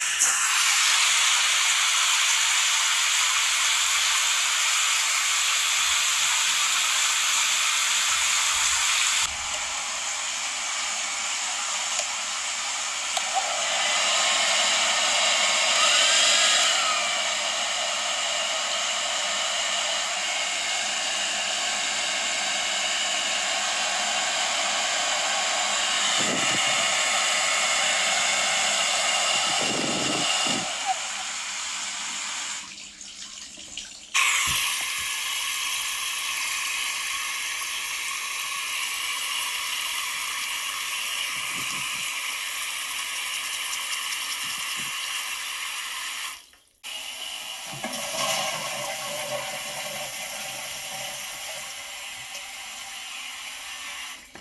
Obraz w ruchu. Interwencja: Audioprzewodnik